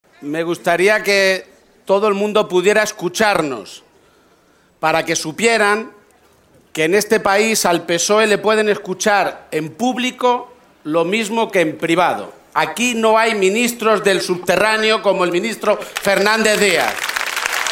Cerca de tres mil personas acudieron al mitin celebrado en la Caseta de los Jardinillos del Recinto de la Feria de Albacete
Cortes de audio de la rueda de prensa